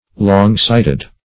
long-sighted - definition of long-sighted - synonyms, pronunciation, spelling from Free Dictionary
Long-sighted \Long"-sight`ed\, a.